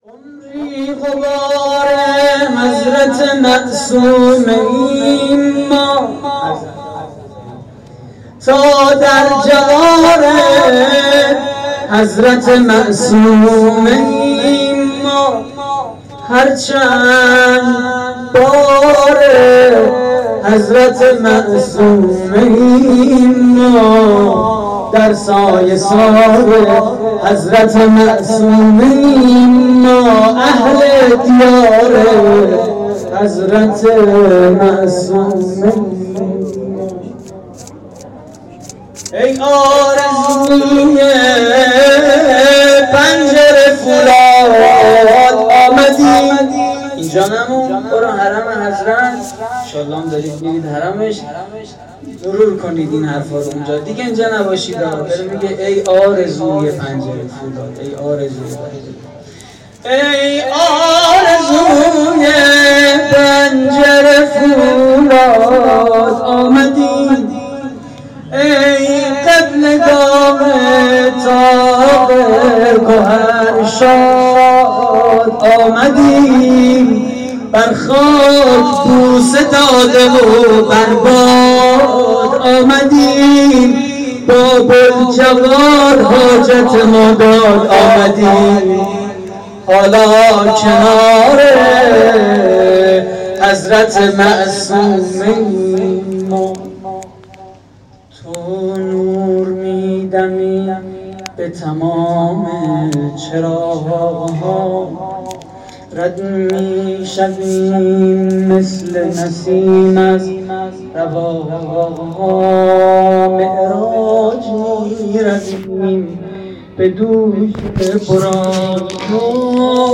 مدیحه: عمری غبار حضرت معصومه ایم ما
مراسم جشن ولادت حضرت معصومه (س) / هیئت کانون دانش‌آموزی حضرت سیدالکریم (ع)؛ شهرری